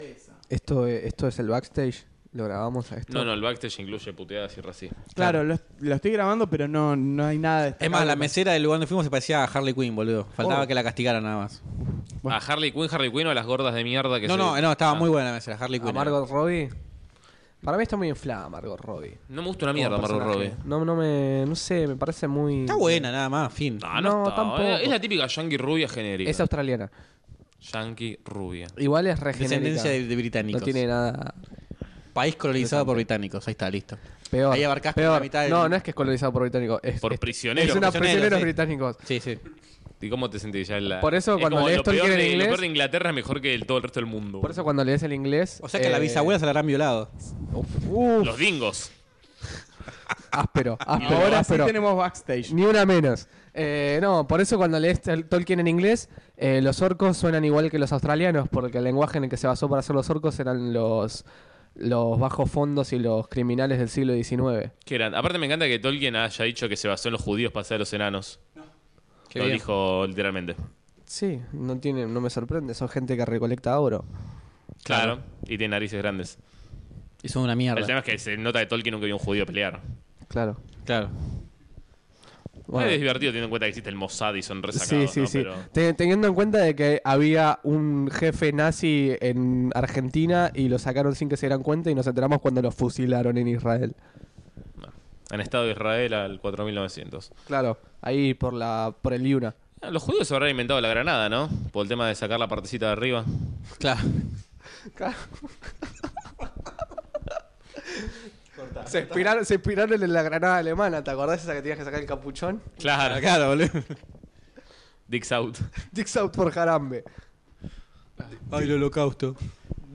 Un poco de la previa de la grabación.